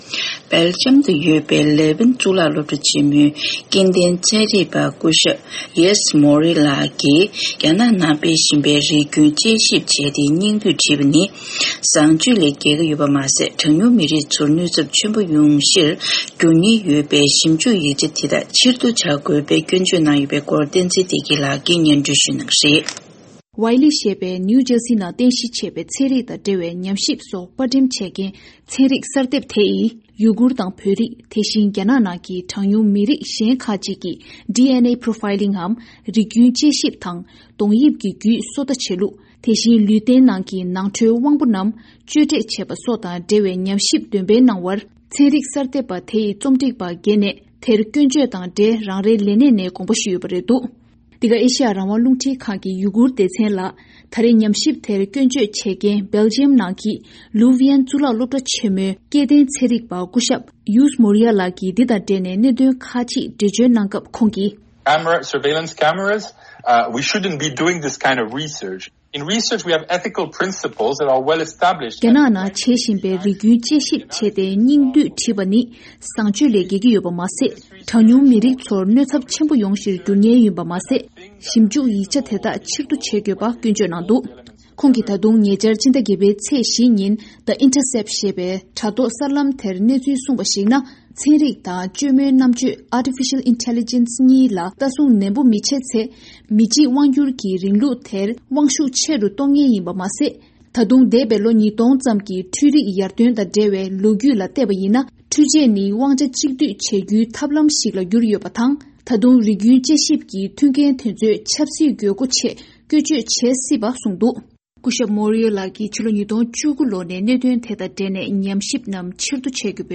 གསར་འགོད་པ།